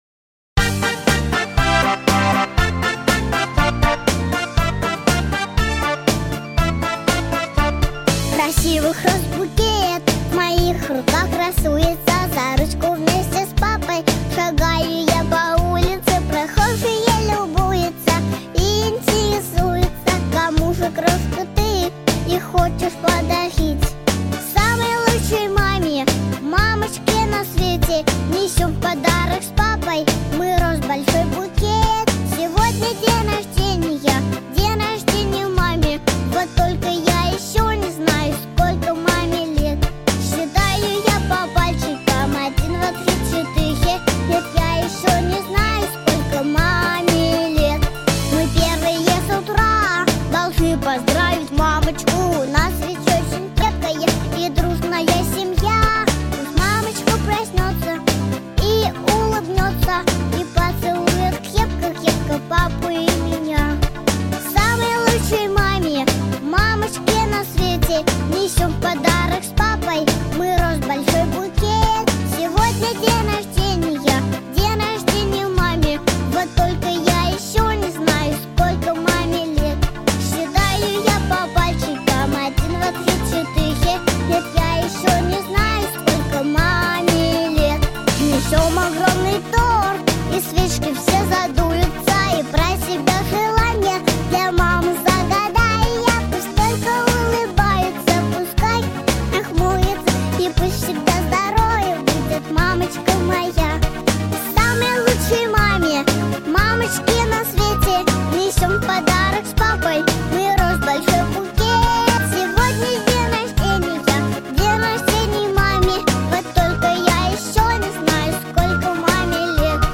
🎶 Детские песни / Песни про маму / День рождения 🎂